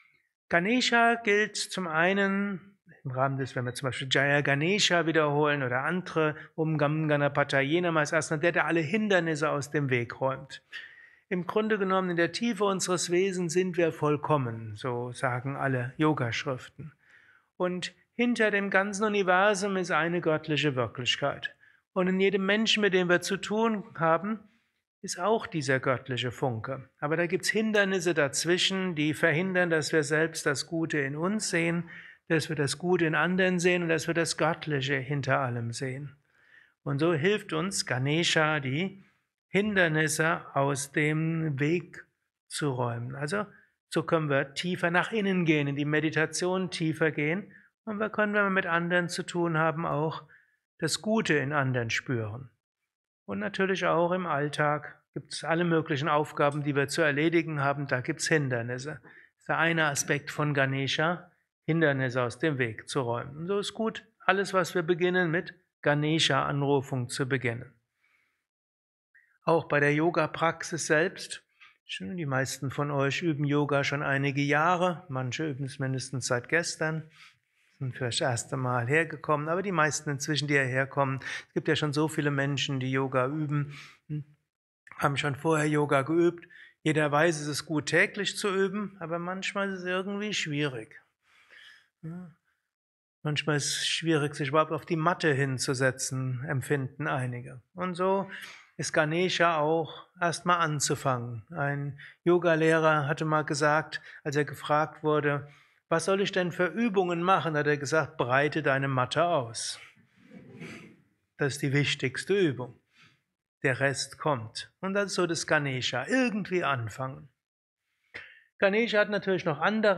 Kurzvorträge
eines Satsangs gehalten nach einer Meditation im Yoga Vidya
Ashram Bad Meinberg.